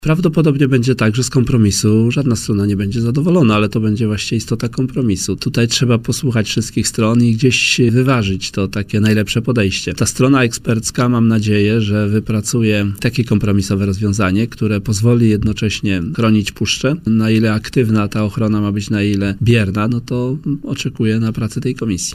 Minister Środowiska, Henryk Kowalczyk, zaznaczył, w poranku „Siódma9” na antenie Radia Warszawa, że osobiście w styczniu nadzorował prace w Puszczy Białowieskiej.